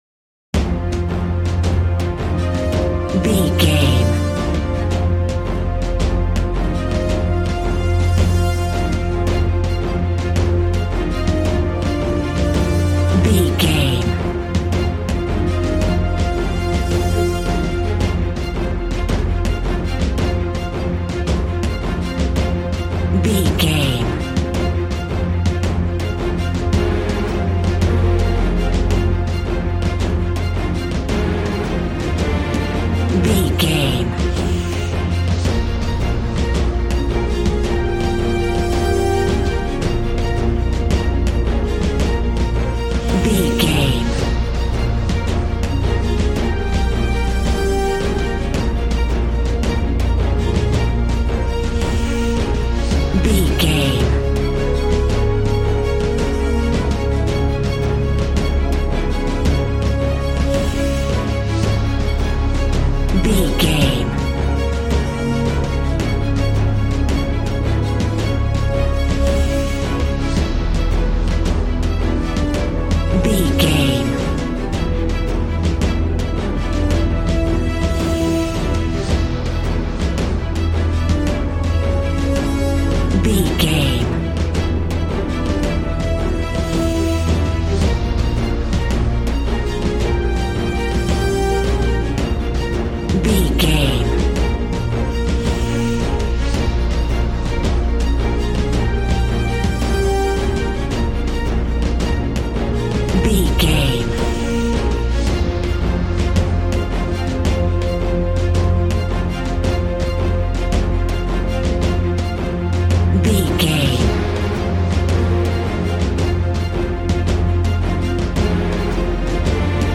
Epic / Action
Fast paced
In-crescendo
Uplifting
Aeolian/Minor
B♭
strings
brass
percussion
synthesiser